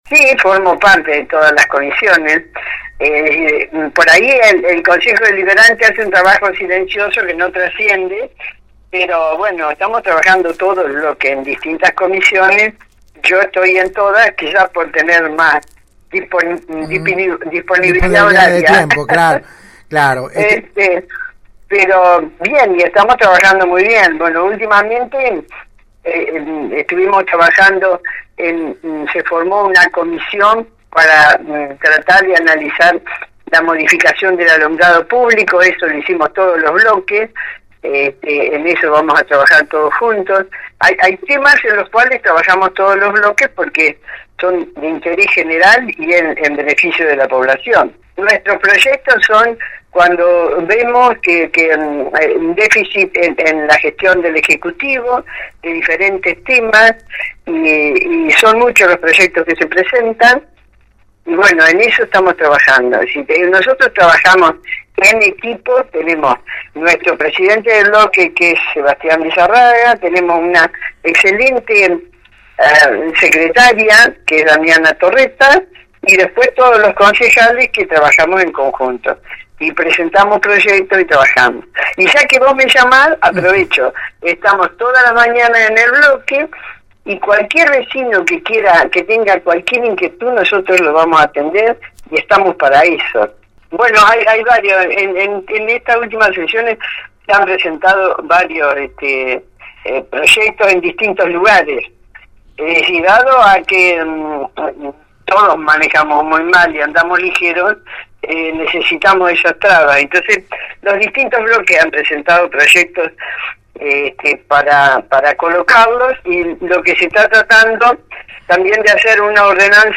A horas de una nueva sesión ordinaria del HCD local la 91.5 habló con la concejal por el Bloque Adelante-Juntos, quien se refirió a los temas tratados en Comisión y que formarán parte este jueves en el orden del día.